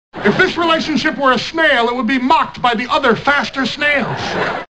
Category: Television   Right: Personal